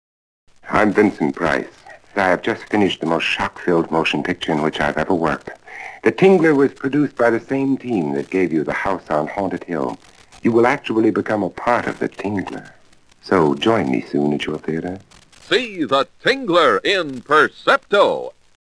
10, 20, 30, and 60 second radio spots
The-Tingler-Vincent-Price-20-converted.mp3